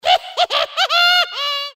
Звуки клоунов
Зловещий звук хихиканья злого клоуна